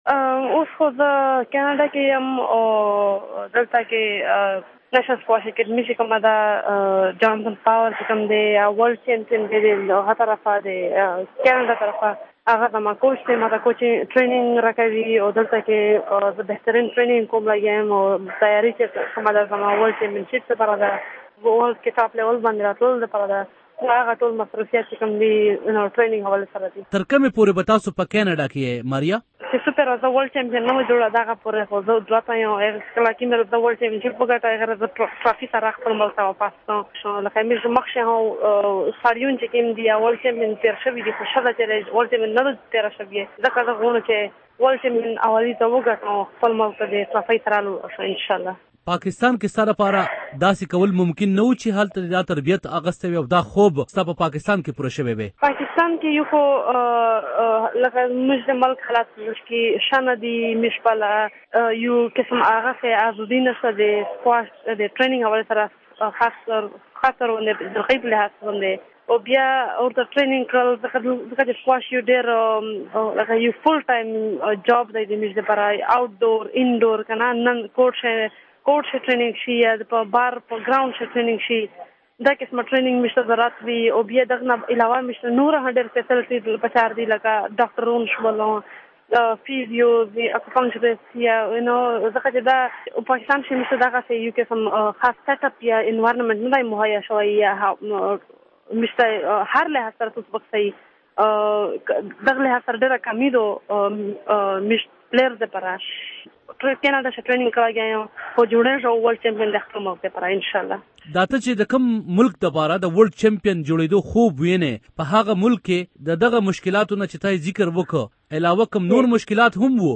22 کلنه ماریه دا وخت په کینیډا کې ده او د ډیوه ریډیو سره د خبرو په وخت ېي دغه ژمنه کړی ده چې نه یواځې ورلډ چمپین جوړیدل ېي د ورکوټوالي ارمان دی ولې واېي وس هغه سوچ ته هم ماتې ورکول غواړي چې ګڼې په ښځو قدغن پکار دی او ښځو له آزادي ورکول بې غېرتي گڼي.